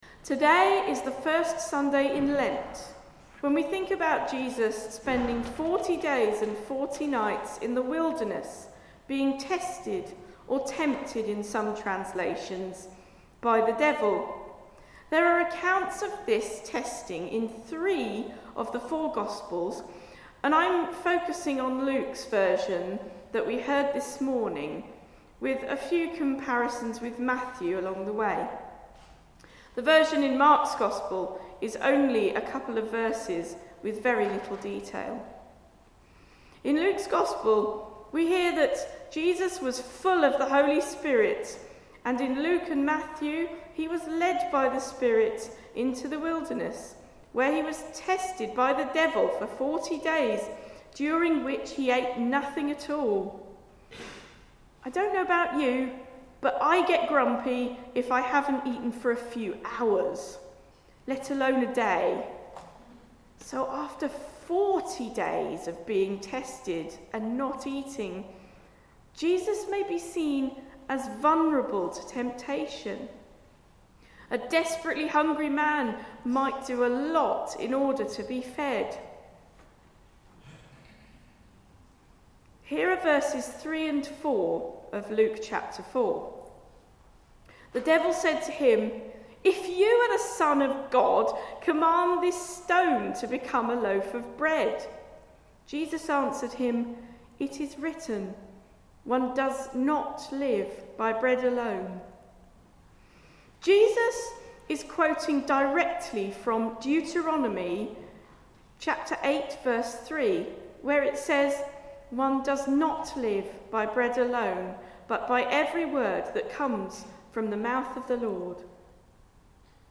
Sermon for the first Sunday in Lent, 9 March 2025